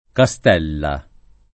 Castella [ ka S t $ lla ]